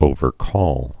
(ōvər-kôl)